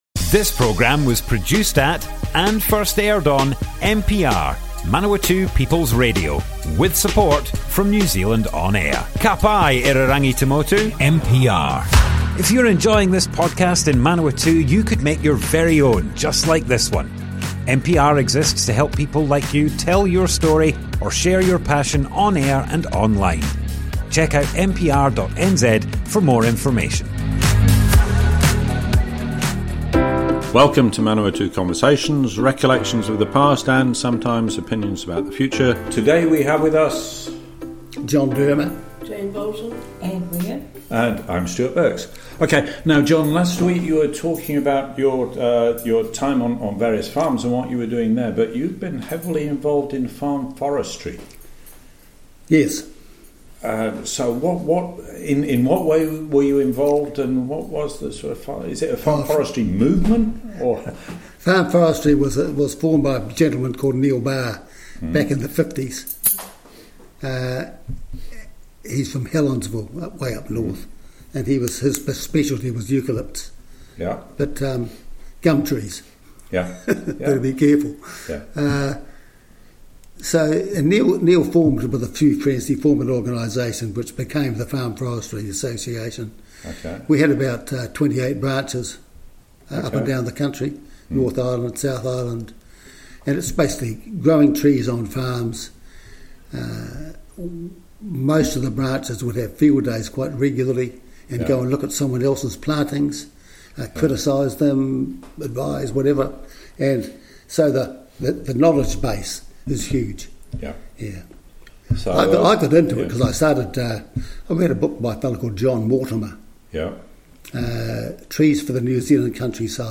Manawatu Conversations Object type Audio More Info → Description Broadcast on Manawatu People's Radio
oral history